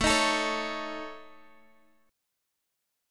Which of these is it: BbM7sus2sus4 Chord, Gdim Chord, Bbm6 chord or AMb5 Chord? AMb5 Chord